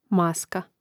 màska maska